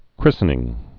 (krĭsə-nĭng)